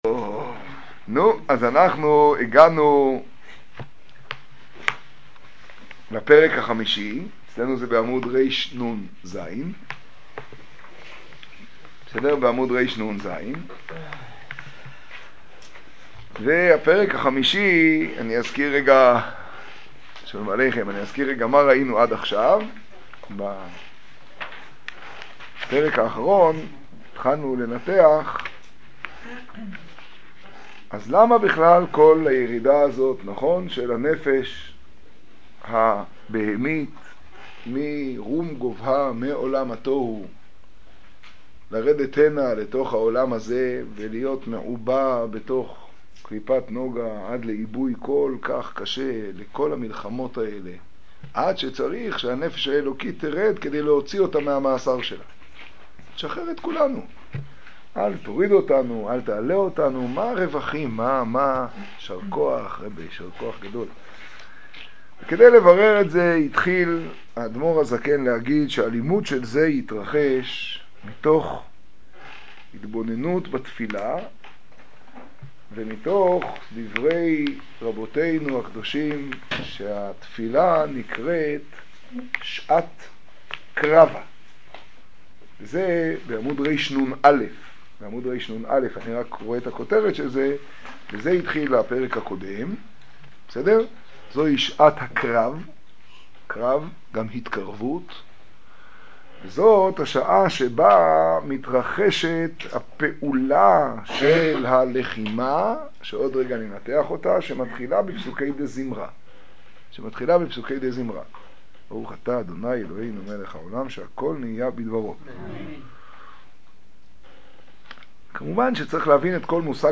השיעור בצפת, פרשת בשלח תשעד.
קטגוריה: שיעור, שיעור בצפת, תוכןתג: בשלח, חומש, שמות, תניא, תשעד